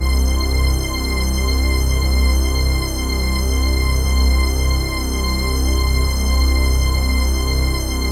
HOUSPAD08.wav